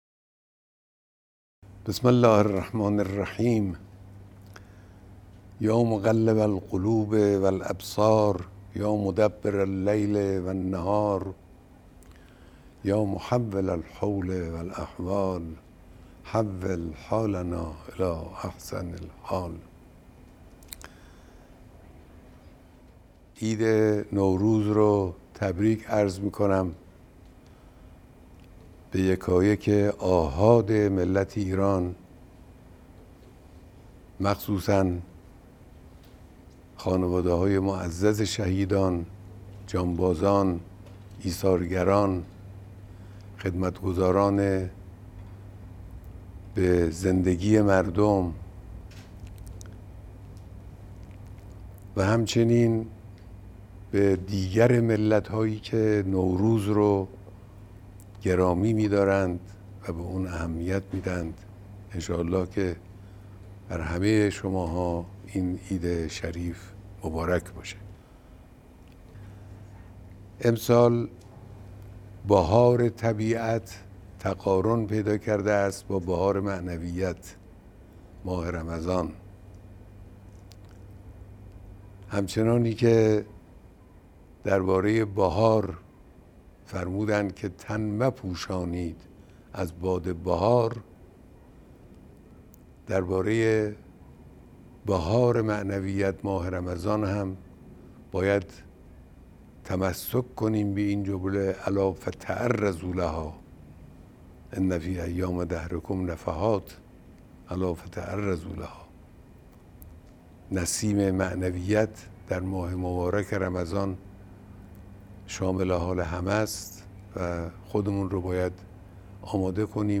پیام رهبر معظم انقلاب اسلامی به مناسبت حلول سال نو